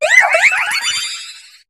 Cri de Strassie dans Pokémon HOME.